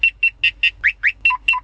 Wazealert2.wav